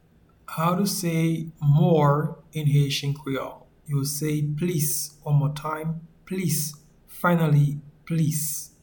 Pronunciation and Transcript:
More-in-Haitian-Creole-Plis.mp3